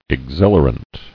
[ex·hil·a·rant]